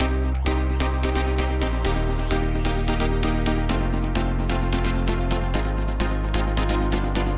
描述：一个技术性的吉他变体。
Tag: 150 bpm Industrial Loops Guitar Electric Loops 2.15 MB wav Key : Unknown